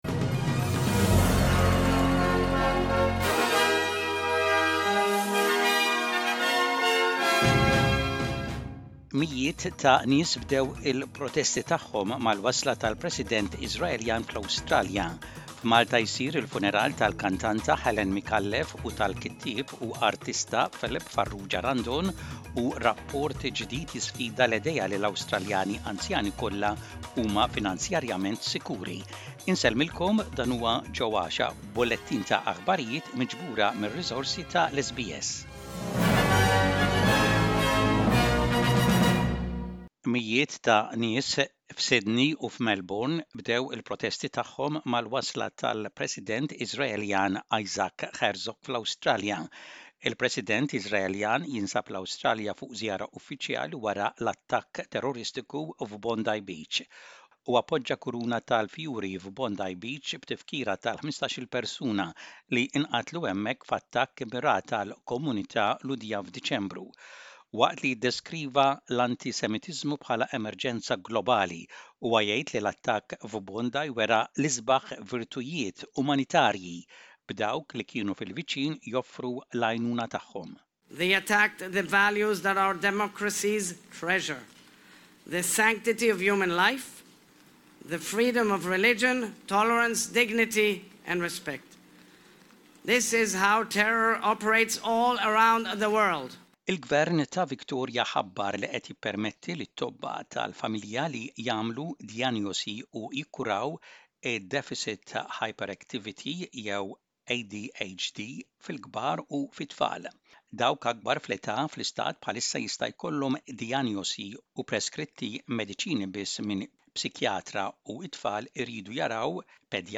SBS Aħbarijiet bil-Malti: 10.02.26